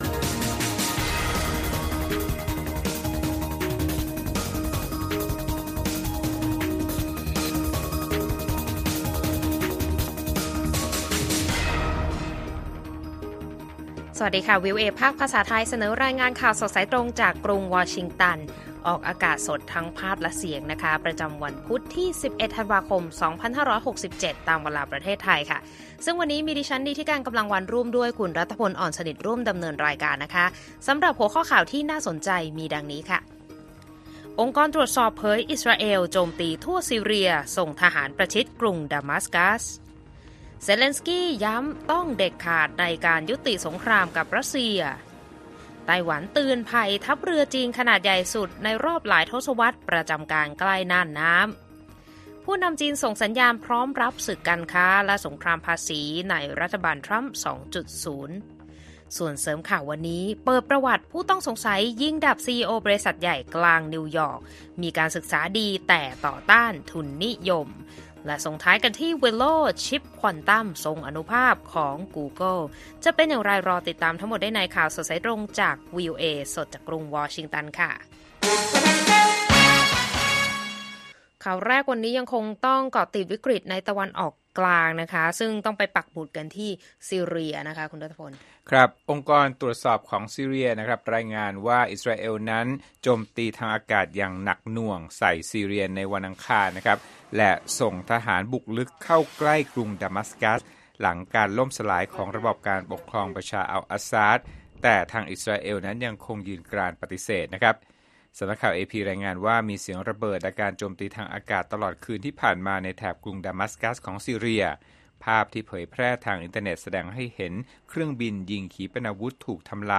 ข่าวสดสายตรงจากวีโอเอไทย พุธ ที่ 11 ธ.ค. 67